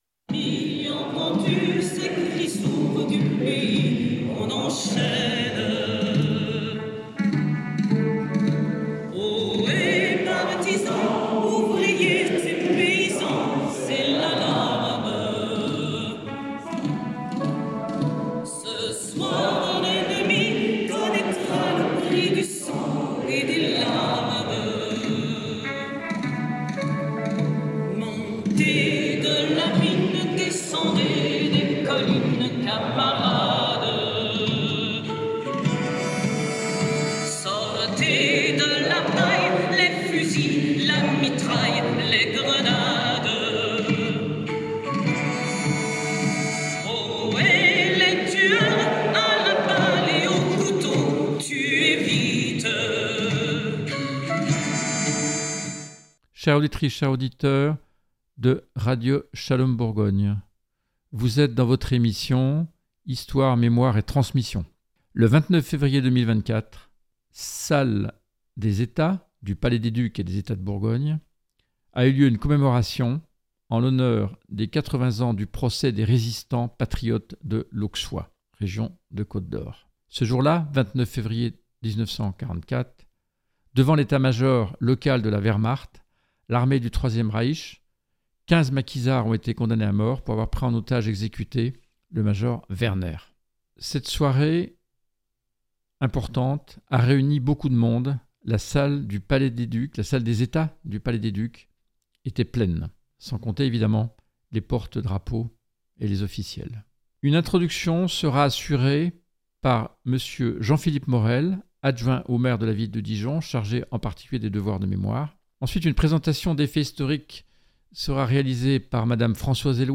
Reportage sur place avec les interviews de :